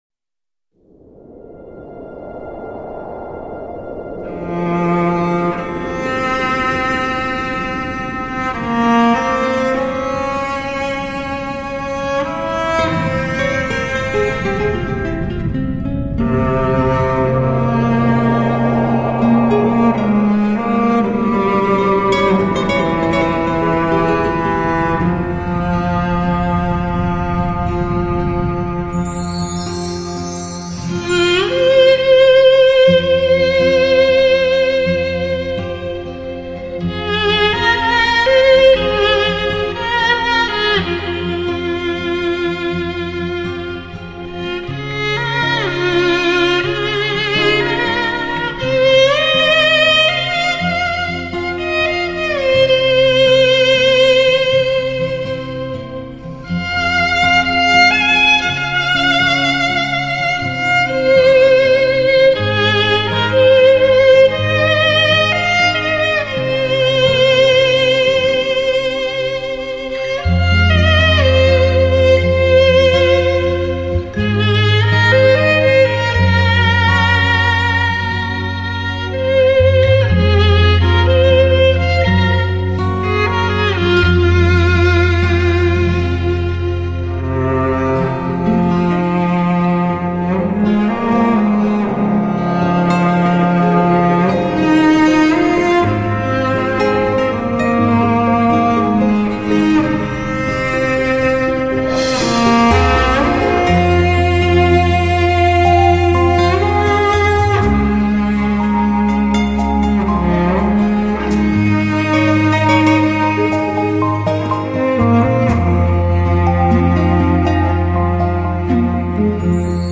专辑格式：DTS-CD-5.1声道
绚丽的纯音华彩乐章，弦弦动听，曲曲动人，追忆那千古的传说......
STS Magix Virtual Live高临场感CD！